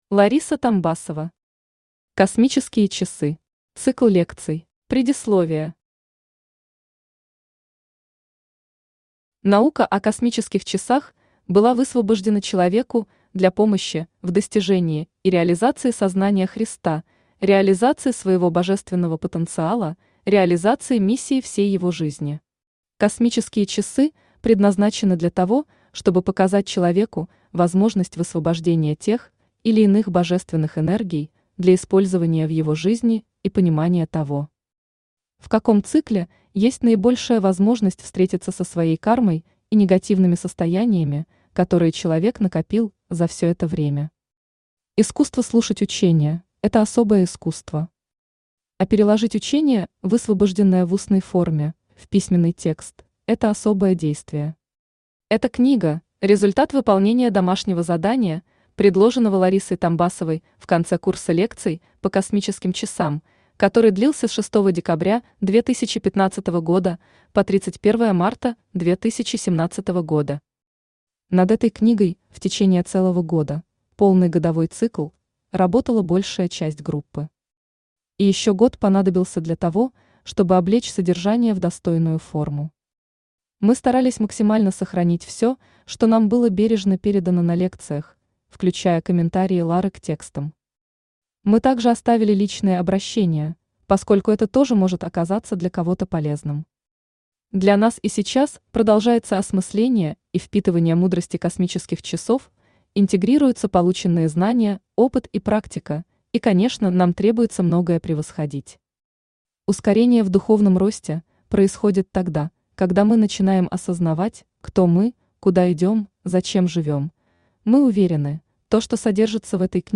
Цикл лекций Автор Лариса Тамбасова Читает аудиокнигу Авточтец ЛитРес.